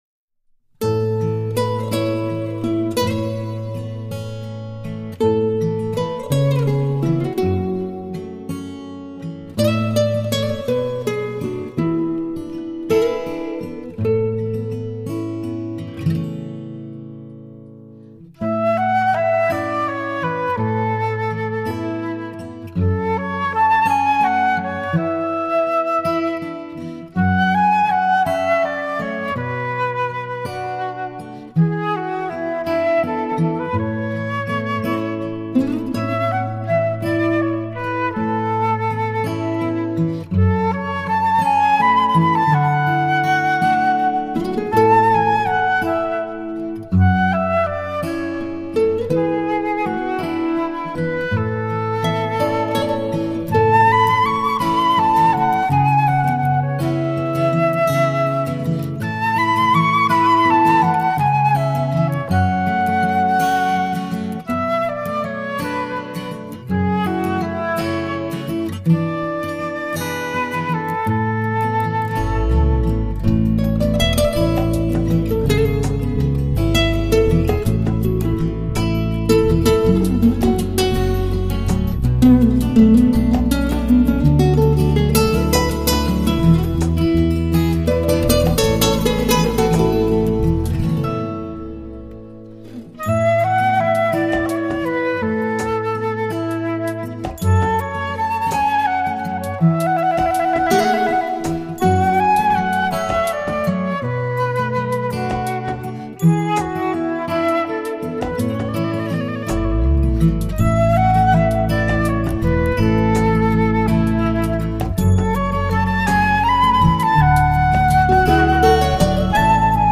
流行和世界音乐风格的结合
笛